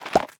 Minecraft Version Minecraft Version latest Latest Release | Latest Snapshot latest / assets / minecraft / sounds / block / beehive / exit.ogg Compare With Compare With Latest Release | Latest Snapshot